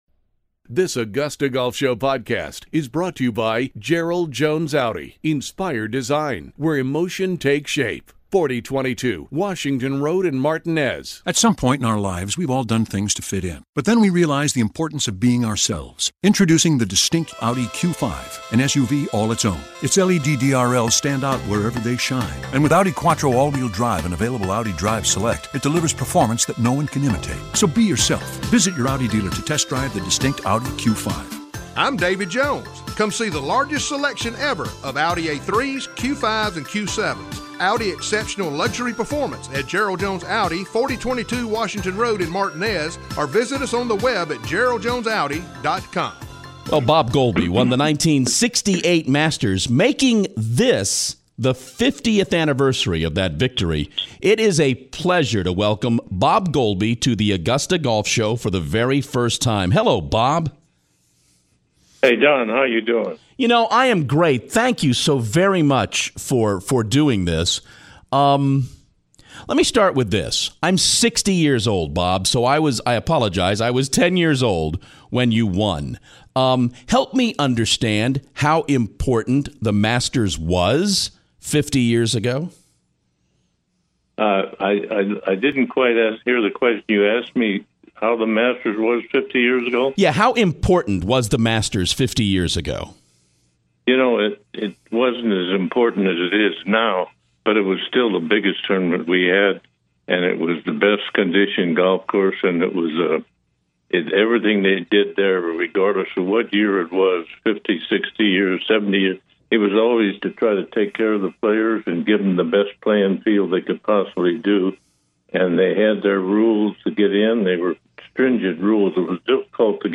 Bob Goalby, the 1968 Masters winner is on the show for the first time, talking about that victory and the celebration surrounding the 50th anniversary.